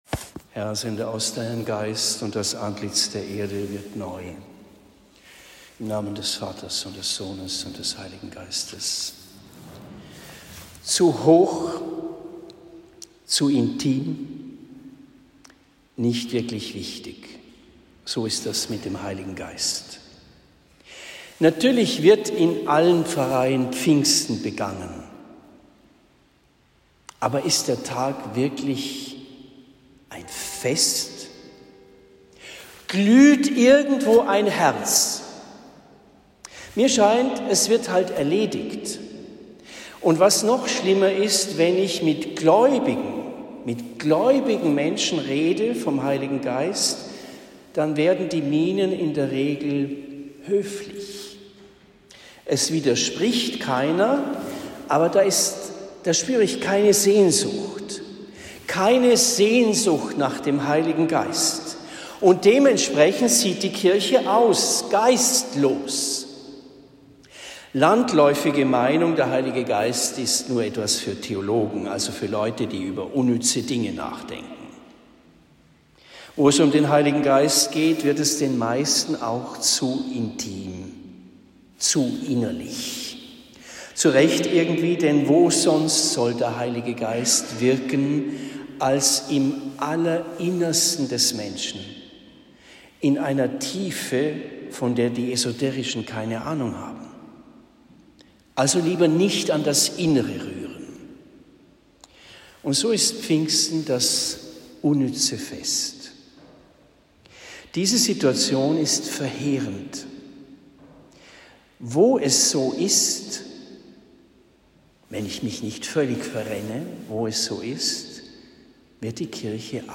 Pfingsten 2023 – Predigt in Homburg am Main